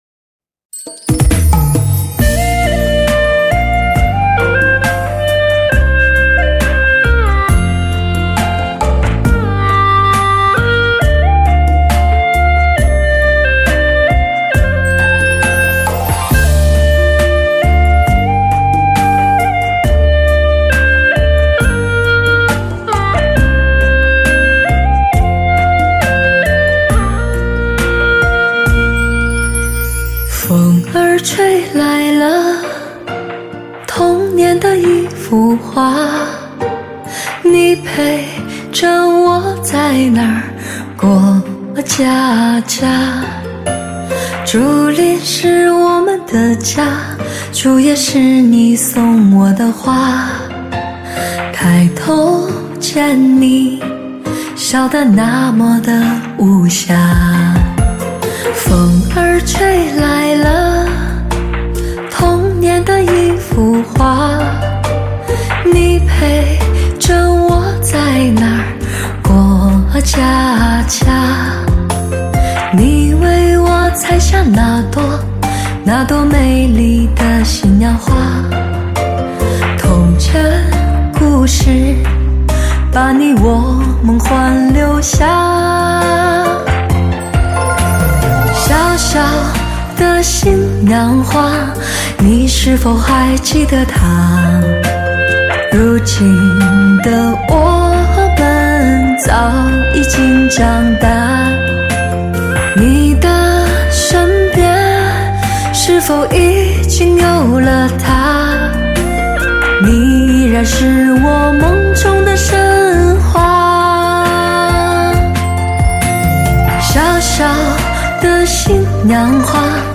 专辑格式：DTS-CD-5.1声道
开创革命性的 STS+SRS全方位环绕HI-FIAUTO SOUND 专业天碟，
专有STS Magix 母带制作，STS magix virtual live高临场感CD。